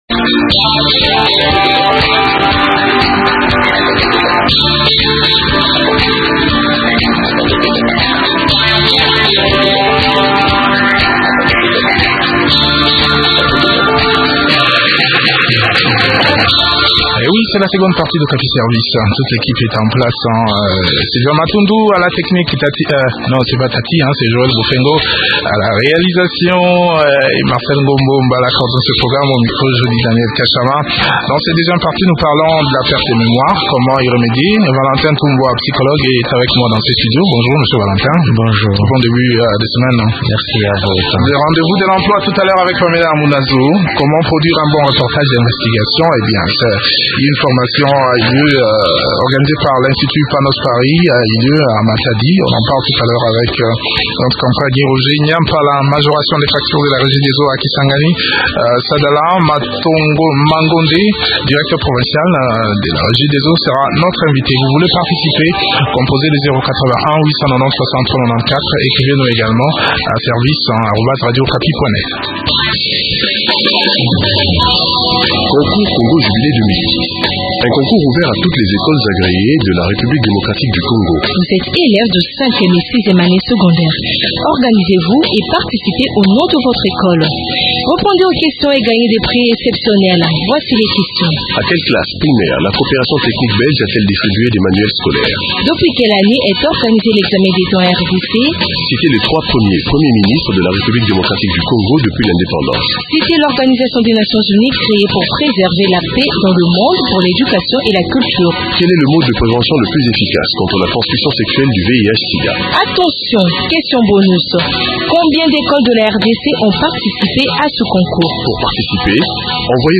Les causes et les remèdes pour soigner cette pathologie sont expliqués dans cet entretien